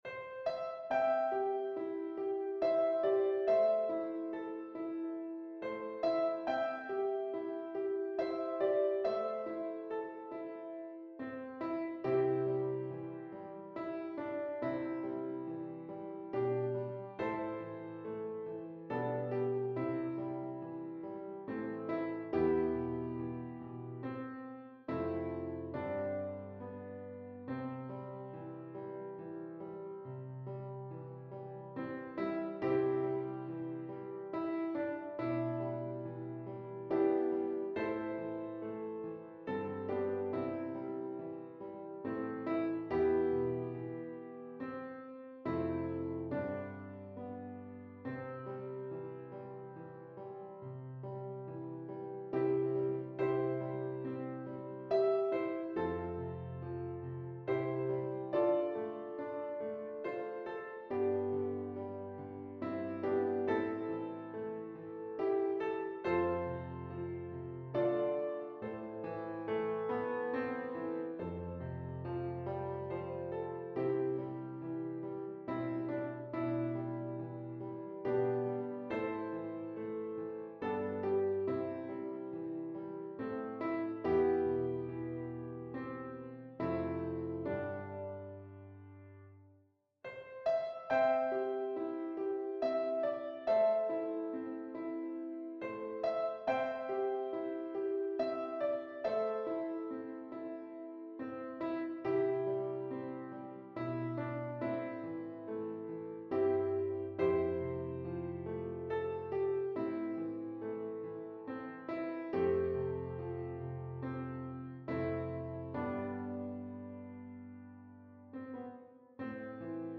This piano arrangement is the result of one of those beautifully arranged hymns. My Shepherd Will Supply My Needs is a fresh variation of the 23rd Psalm.